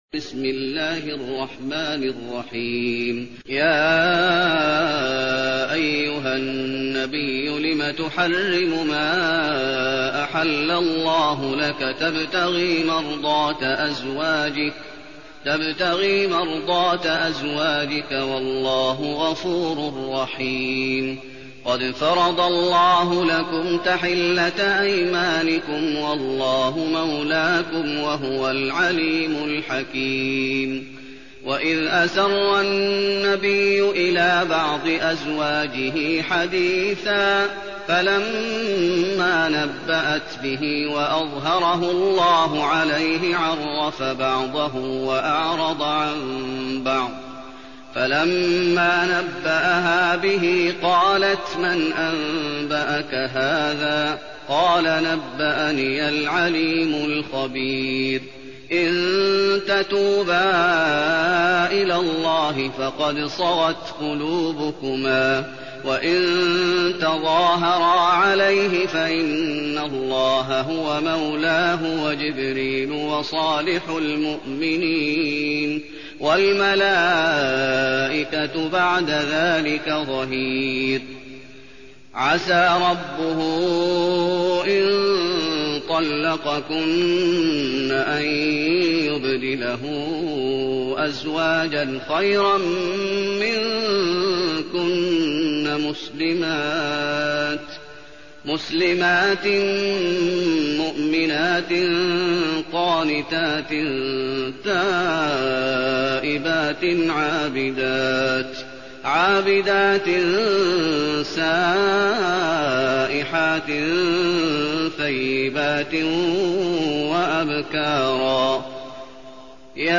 المكان: المسجد النبوي التحريم